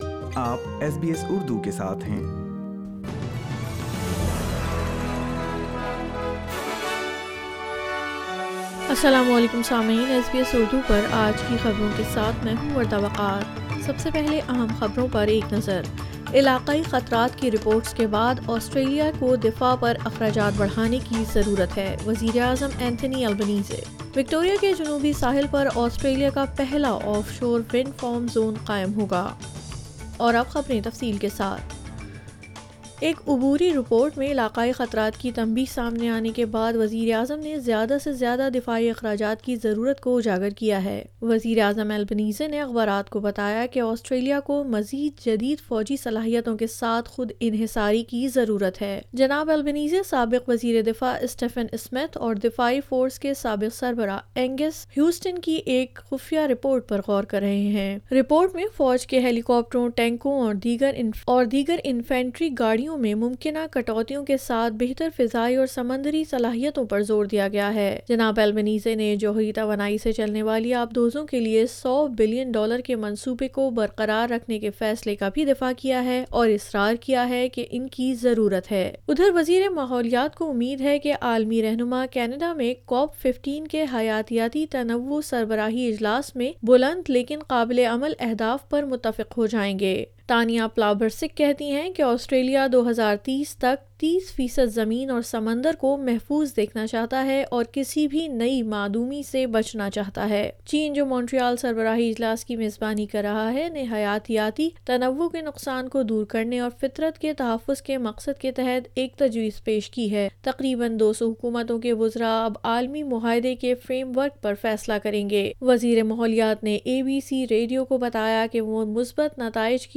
Urdu News 19 December 2022